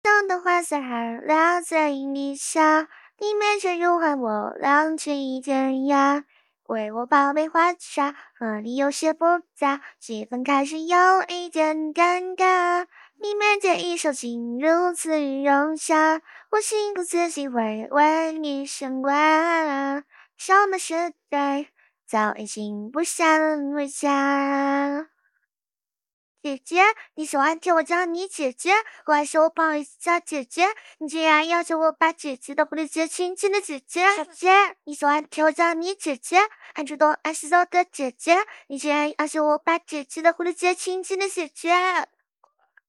唱歌表现
有两首歌+剪到了一块，可以听一听，只能唱这首类型的歌，原始音色也要可可爱爱才最合适，适当的伪一下。